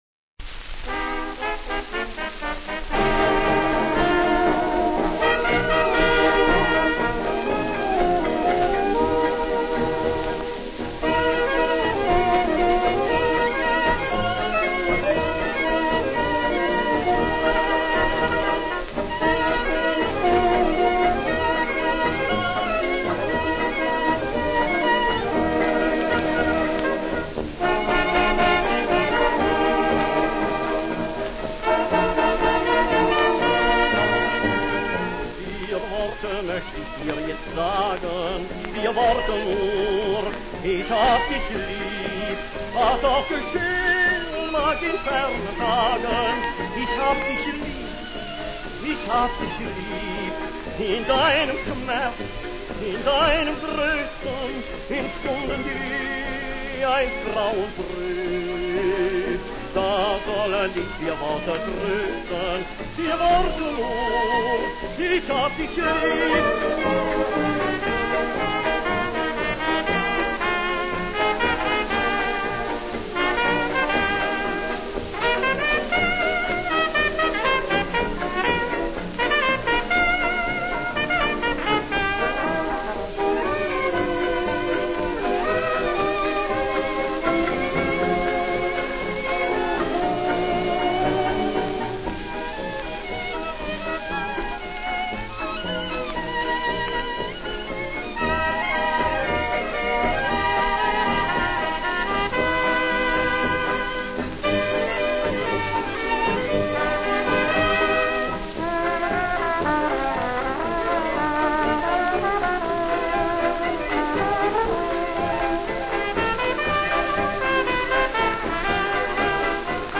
Als Foxtrott ein schöner Titel